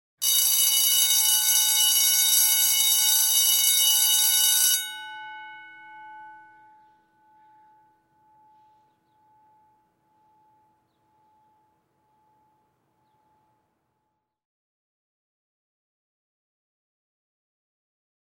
На этой странице собраны разнообразные звуки школьного звонка: от традиционных резких переливов до современных мелодичных сигналов.
Звонок для студентов в техникуме или колледже